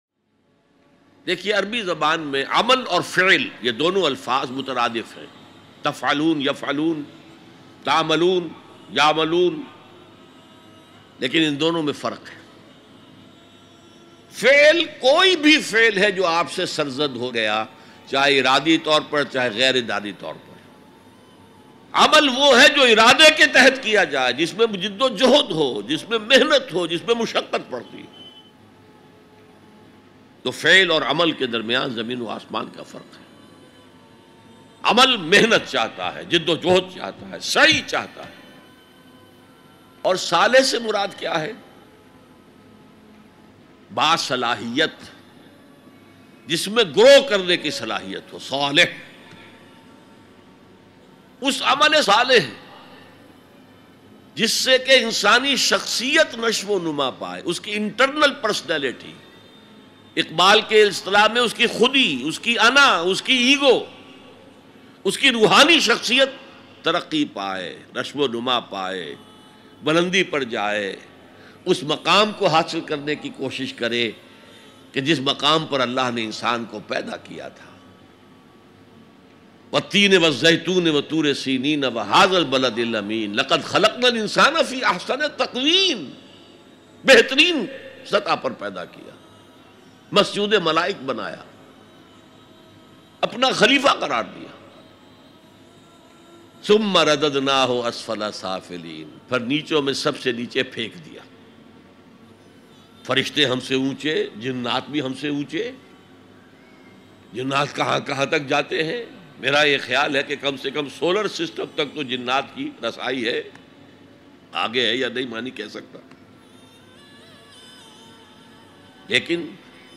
Dr Israr Ahmed Very Beautiful Bayan MP3 Download
Dr-Israr-Ahmed-Very-Beautiful-Bayan.mp3